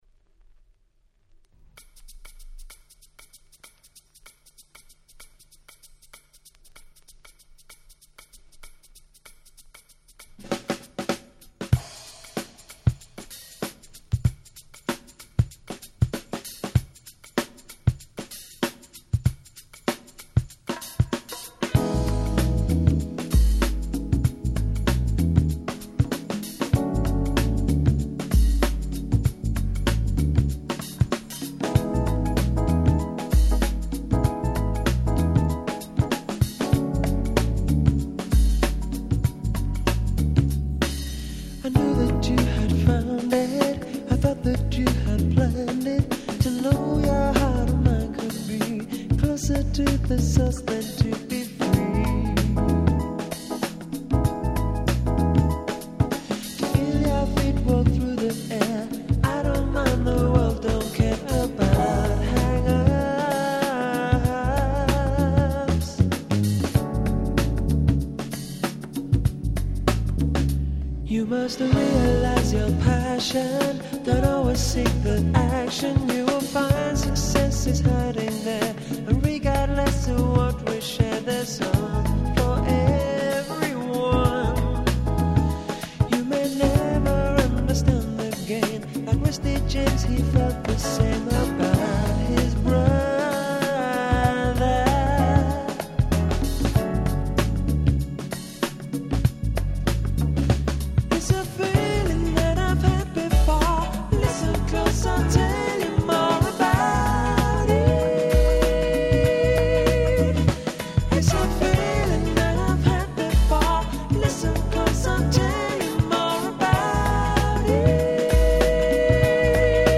93' Nice UK Soul !!